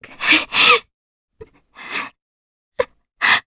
Scarlet-Vox_Sad_jp.wav